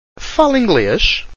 Fahla Ingle[a]ysh? – Although the ‘ê’ sometimes sounds more open (‘ay’), the circumflex still denotes that it should be a closed pronunciation.